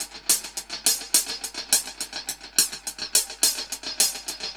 Index of /musicradar/dub-drums-samples/105bpm
Db_DrumsA_HatsEcho_105_01.wav